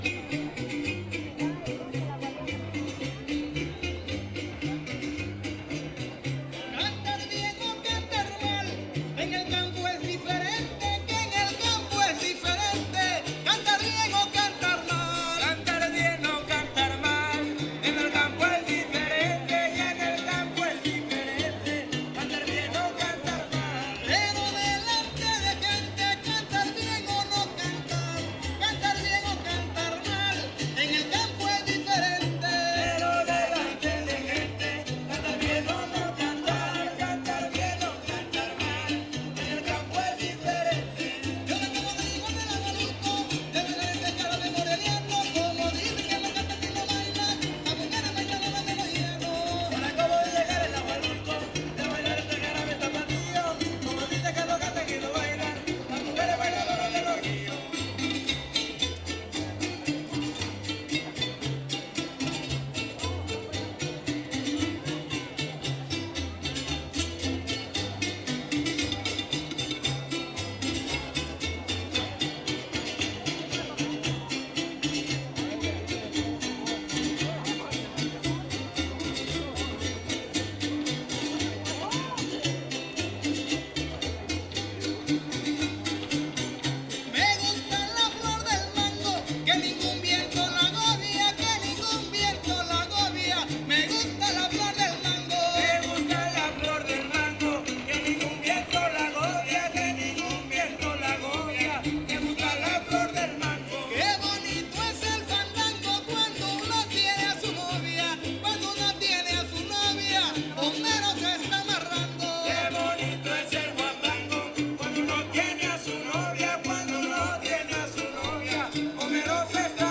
• Siquisirí (Grupo musical)
Concurso Estatal de Fandango